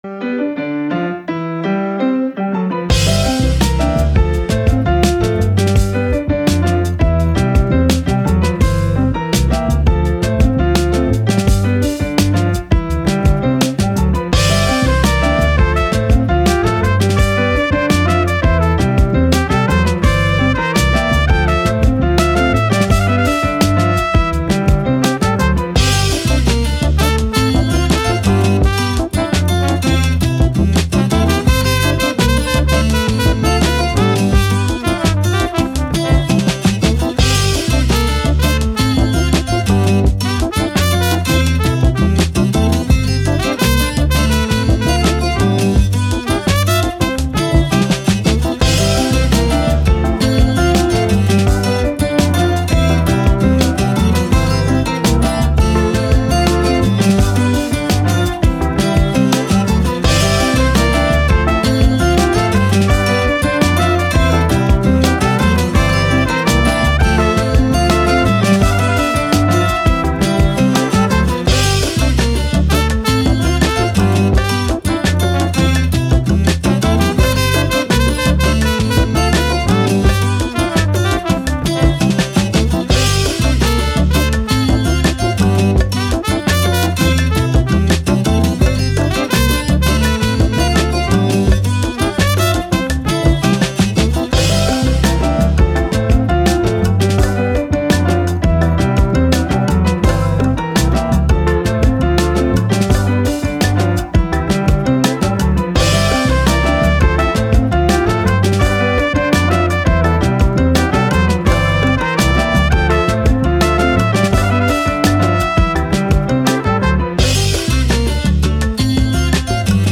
Latin, Hip Hop, Jazz, Upbeat, Positive, Sun